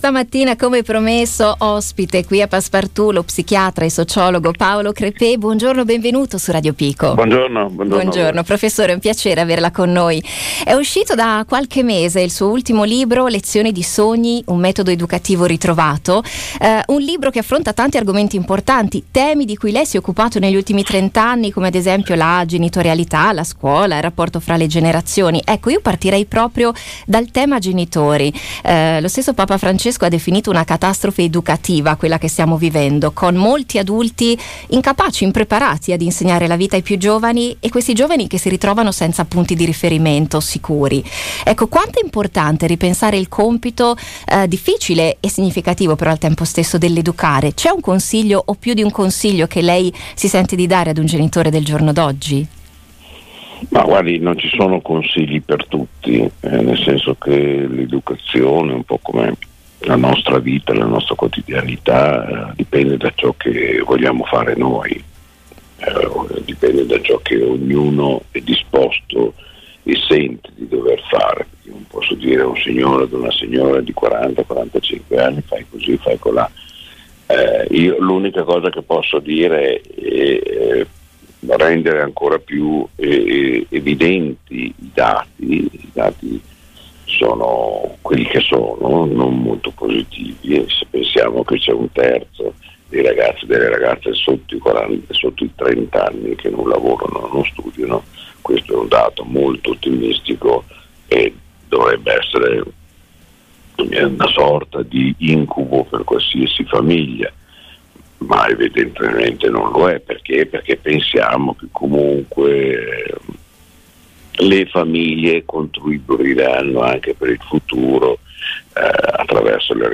Radio Pico intervista lo psichiatra e sociologo Paolo Crepet
Reduce dall’uscita del suo ultimo libro “Lezioni di sogni – Un metodo educativo ritrovato”, lo psichiatra e sociologo Paolo Crepet è stato ospite del programma Passepartout per approfondire i temi affrontati nel suo ultimo volume: genitorialità, scuola, rapporto tra generazioni.